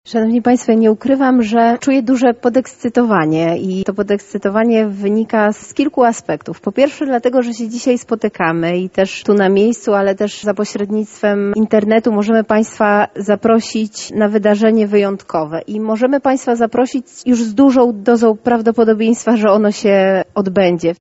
Podczas konferencji w Teatrze Starym poznaliśmy szczegóły zbliżających się wydarzeń.
-To widowisko, którego program jest bardzo bogaty – stwierdza Zastępca Prezydenta Miasta Lublin ds. Kultury, Sportu i Partycypacji Beata Stepaniuk-Kuśmierzak.